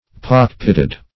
Pock-pitted \Pock"-pit`ted\, a. Pockmarked; pitted.